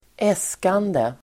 Uttal: [²'es:kande]